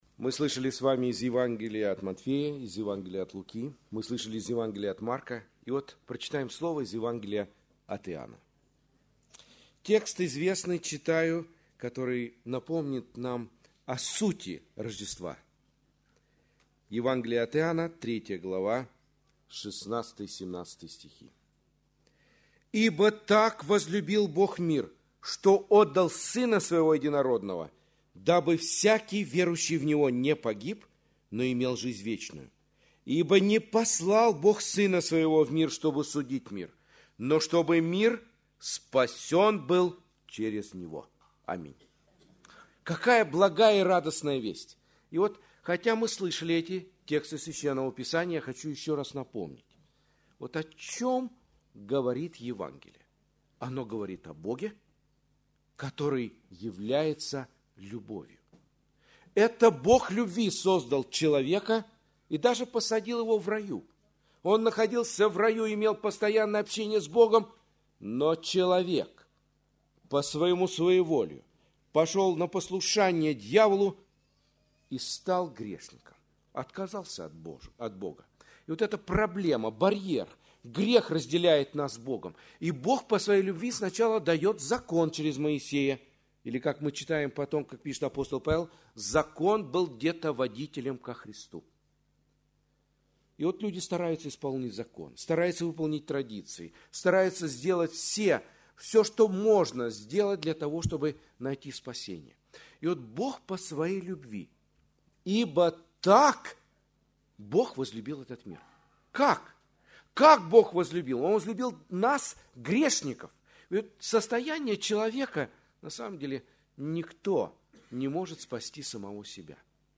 Церковь: Церковь ЕХБ "Голгофа", г. Москва (Местная религиозная организация – Церковь евангельских христиан-баптистов «Голгофа»)